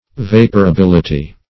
Vaporability \Vap`o*ra*bil"i*ty\, n.
vaporability.mp3